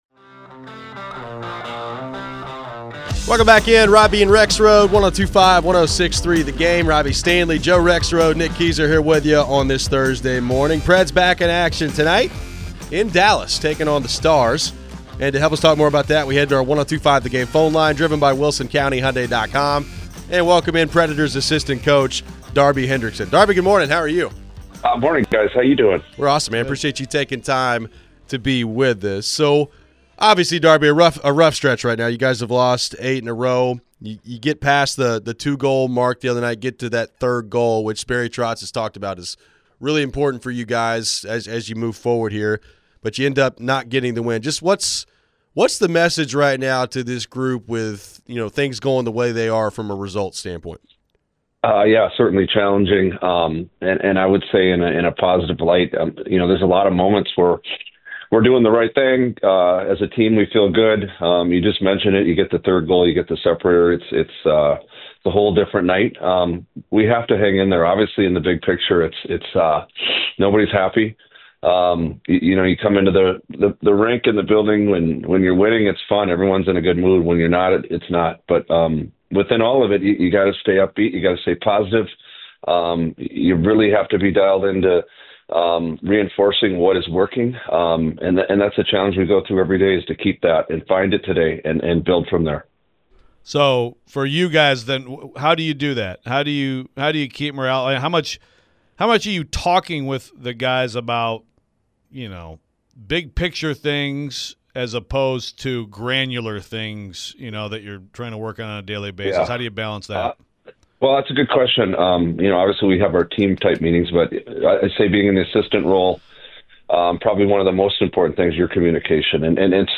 Darby Hendrickson Interview (12-12-24)
Nashville Predators assistant coach Darby Hendrickson joined the show to help preview tonight's game in Dallas. What has Darby made of the young guys stepping in to play? What about Steven Stamkos playing top-line minutes as a center?